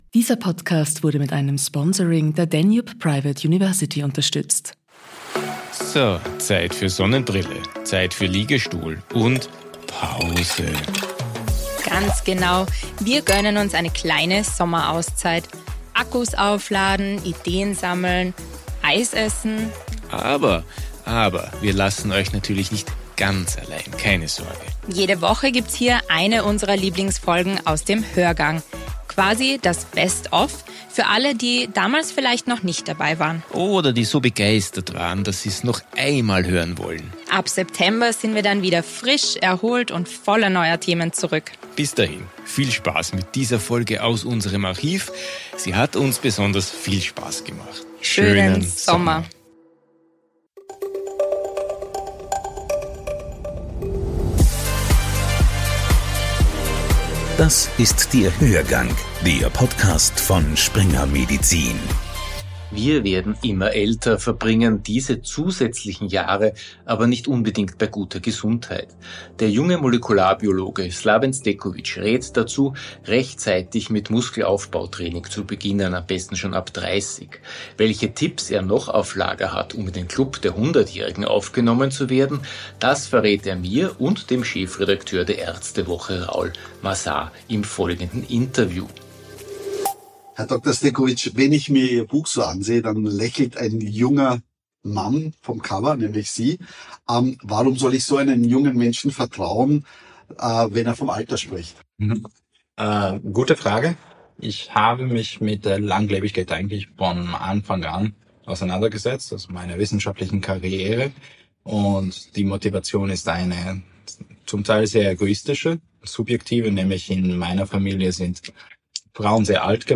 Nun haben wir auf Springer Medizin Österreich einen Podcast ins Leben gerufen, der sowohl aktuell als auch wissensvermittelnd sein, darüber hinaus akustisch etwas hermachen soll. Neben Experten aus Wissenschaft und Praxis werden wir auch unsere Fachredakteure, Kolumnisten und Reporter der „Ärzte Woche“ zu Wort kommen lassen.